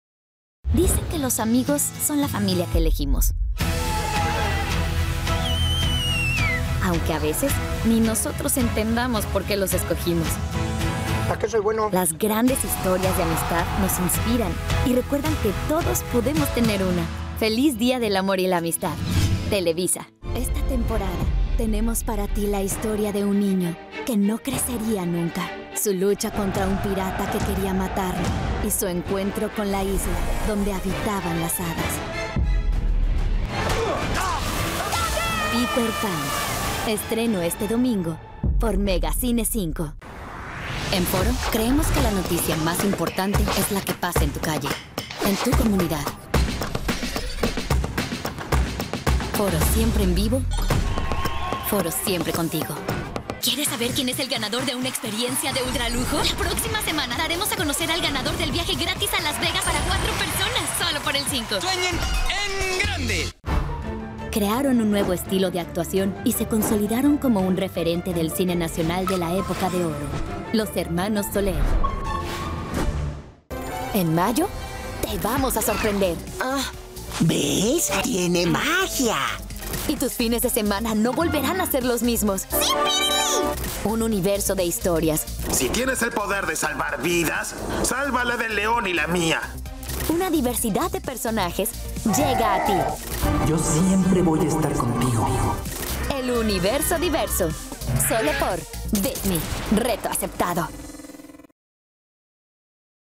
commercial demo reel